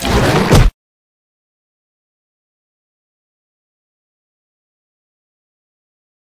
vpunch2.ogg